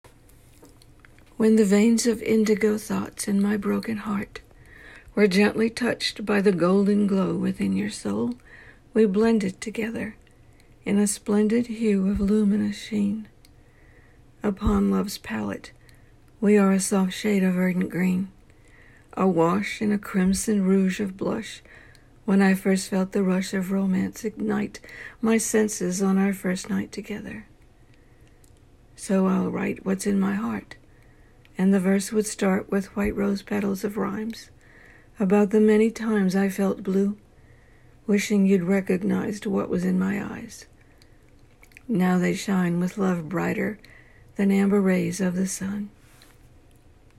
You have a soothing voice.